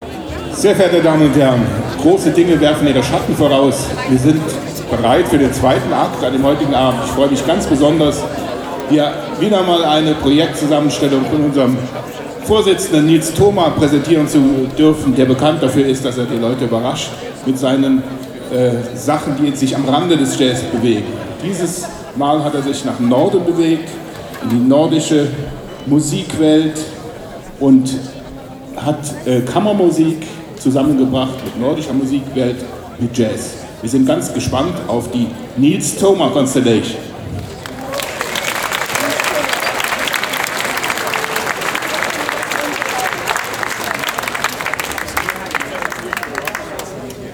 Konzert Regionalabend 31. Jazz im Brunnenhof (Trier)
Begrüßung